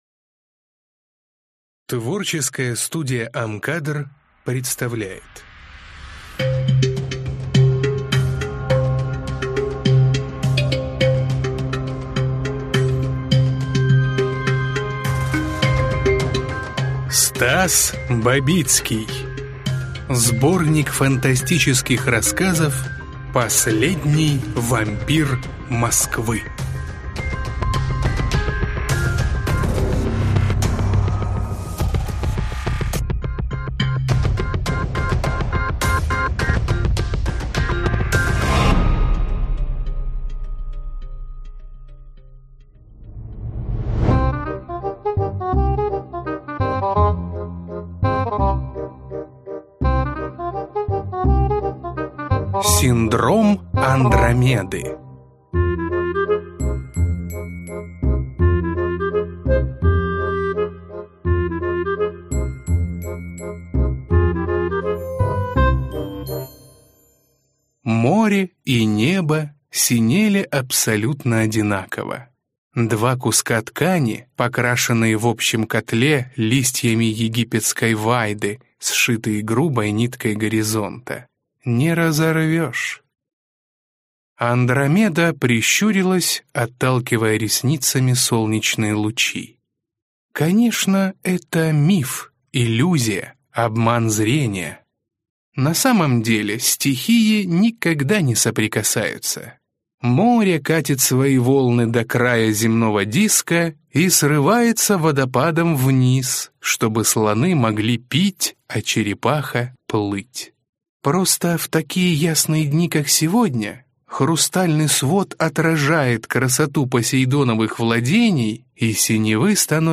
Аудиокнига Последний вампир Москвы | Библиотека аудиокниг
Прослушать и бесплатно скачать фрагмент аудиокниги